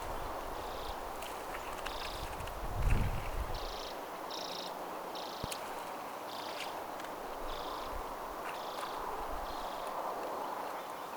urpiaisen ääntelyä laululennossa
tuollaista_urpiaisen_aantelya_ehka_laululennossa.mp3